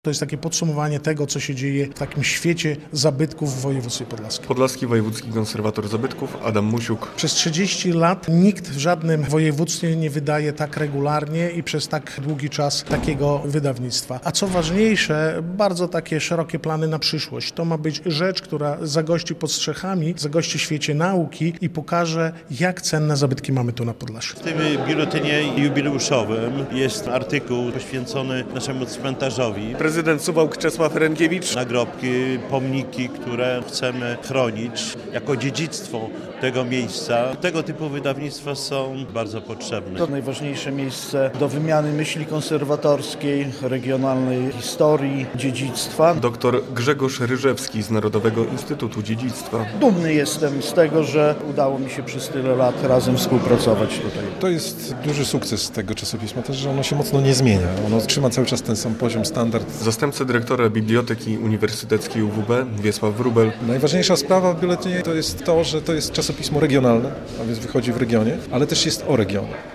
relacja
Jubileusz zgromadził w białostockim Pałacu Hasbacha historyków, samorządowców i ekspertów od ochrony zabytków.